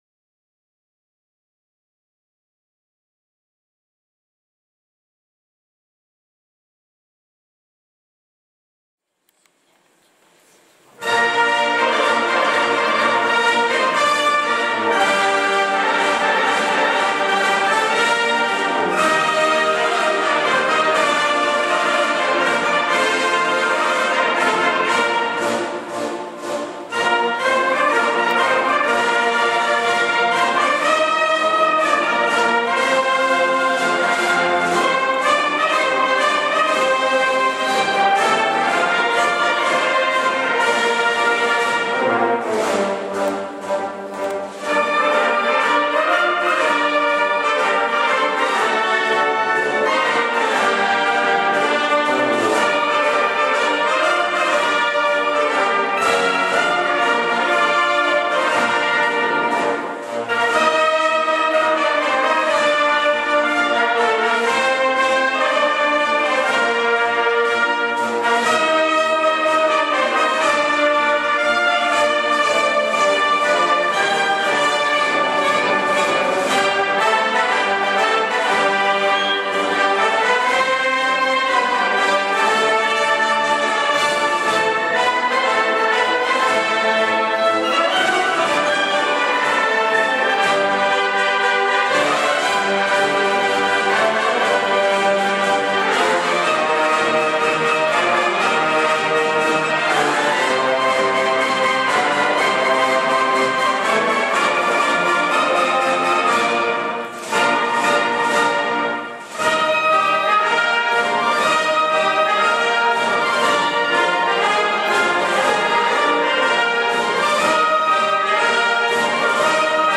80 V Sambre et Meuse RAUSKY Pas redoublé 🖼